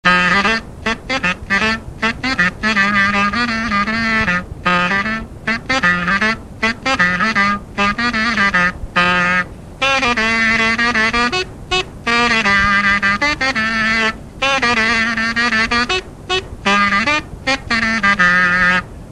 Mazurka
Résumé instrumental
danse : mazurka
Pièce musicale inédite